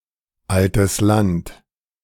Altes Land (German pronunciation: [ˈaltəs ˈlant]